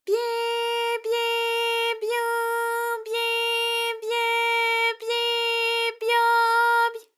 ALYS-DB-001-JPN - First Japanese UTAU vocal library of ALYS.
byi_byi_byu_byi_bye_byi_byo_by.wav